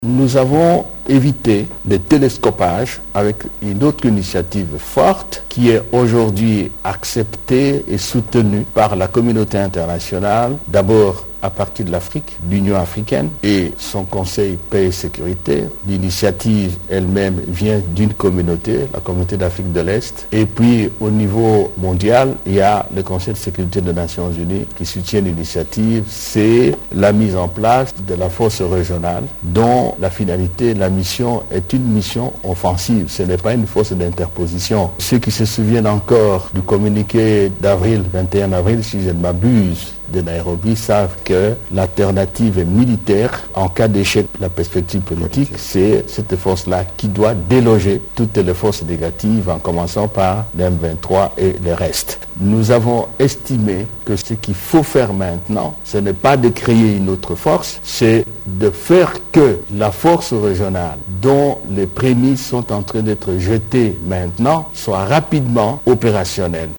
La force régionale de la Communauté de l’Afrique de l’Est (EAC), qui sera « incessamment déployée en RDC, sera une force offensive et non d’interposition », a déclaré samedi 23 juillet le vice-premier ministre, ministre des Affaires étrangères, Christophe Lutundula, au cours de la conférence de presse conjointe organisée avec son collègue de la Communication et des Médias.
Il plaide pour que cette force régionale soit rapidement opérationnelle :